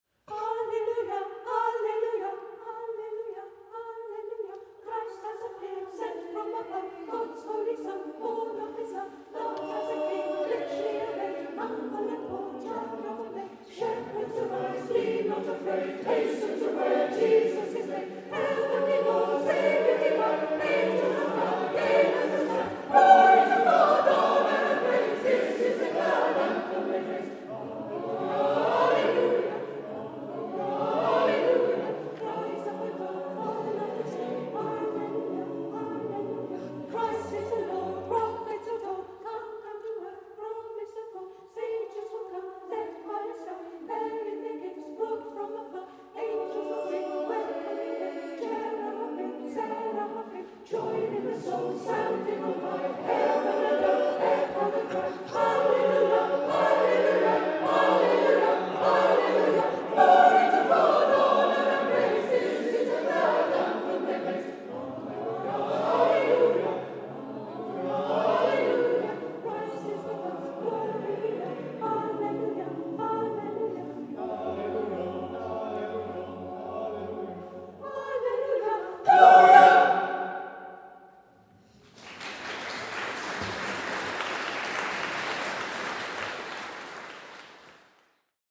Holy Trinity Church in Minchinhampton looked wonderfully festive for our Christmas concert this year.
organ